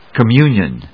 音節com・mu・nion 発音記号・読み方
/kəmjúːnjən(米国英語), kʌˈmju:njʌn(英国英語)/